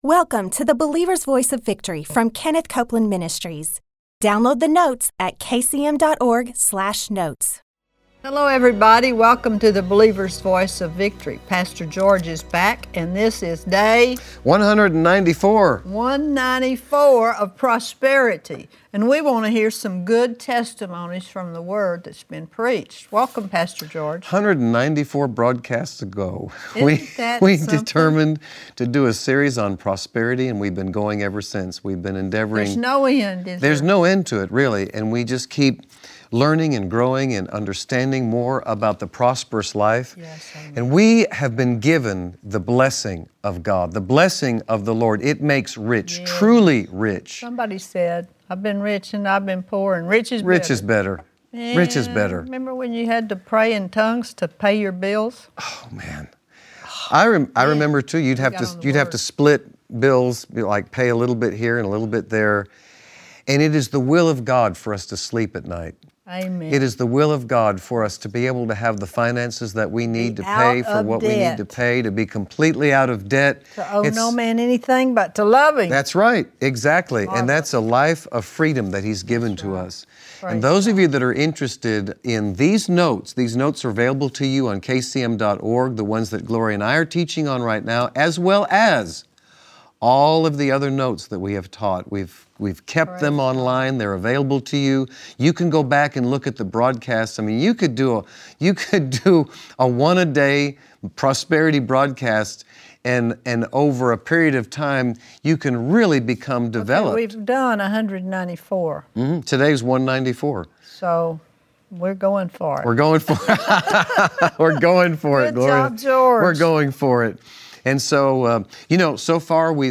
Gloria Copeland and her guest